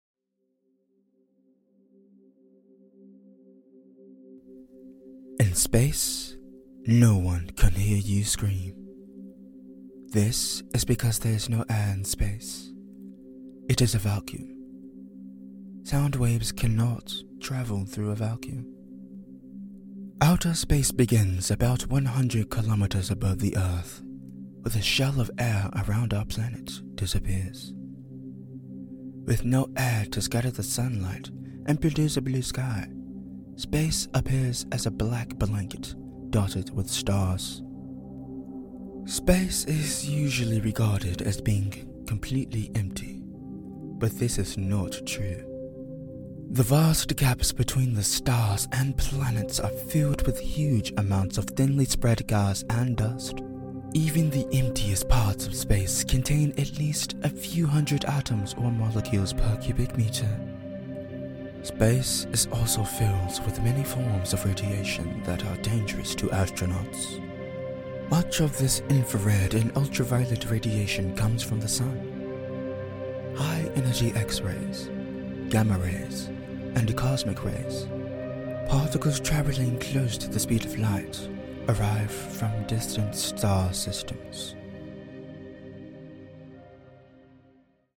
Narration sample #1
Southern English, British English, General north American English , African American/Midwest
Teen
Young Adult